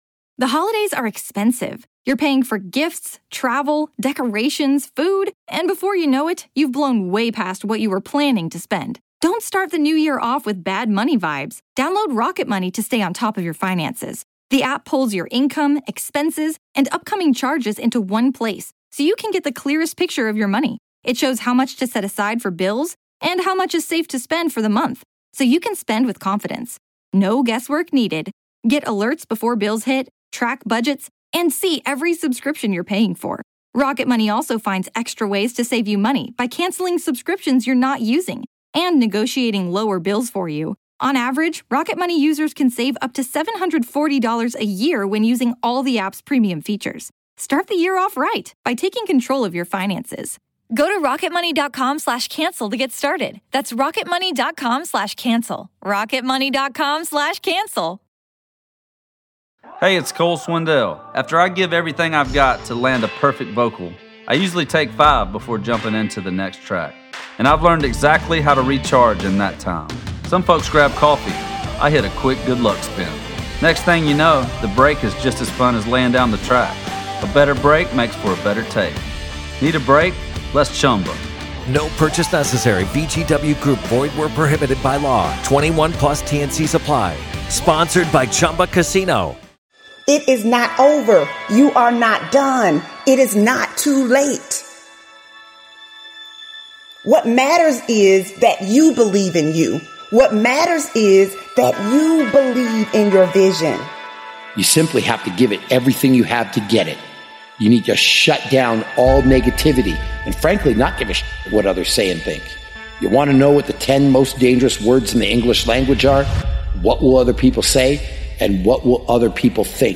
Speaker: Les Brown